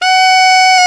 SAX A.FF F0K.wav